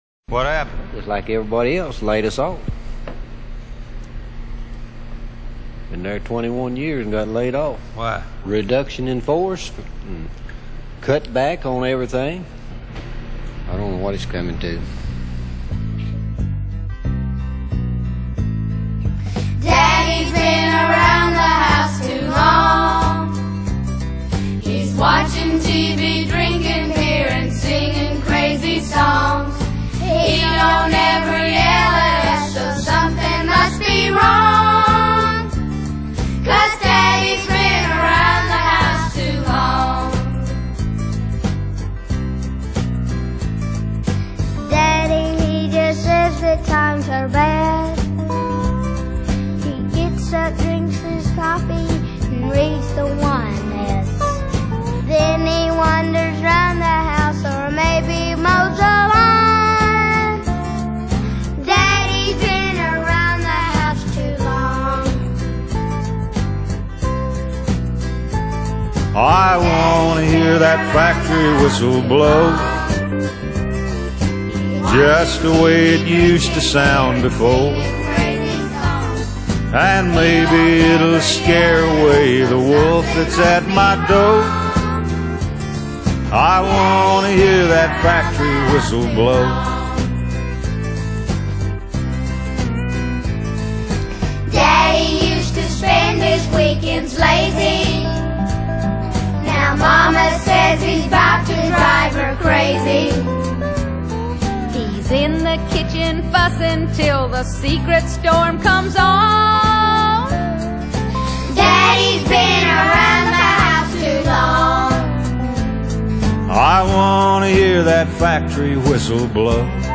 美國鄉村歌手。